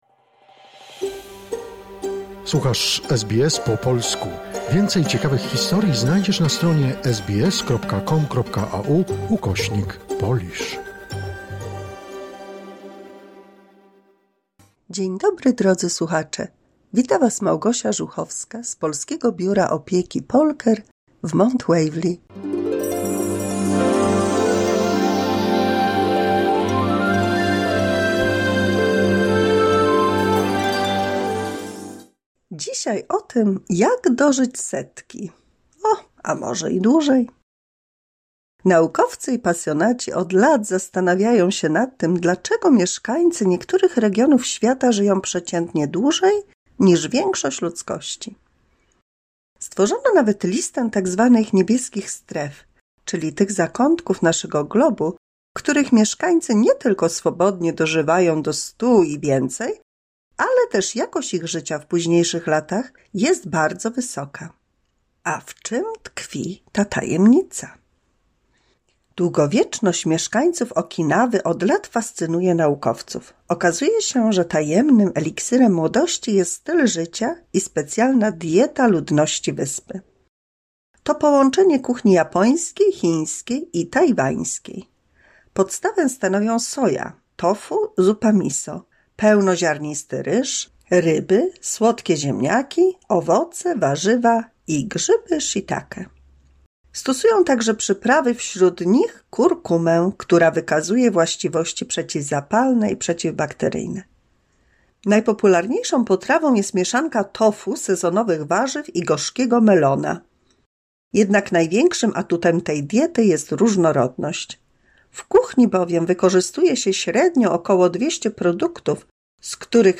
171 mini słuchowisko dla polskich seniorów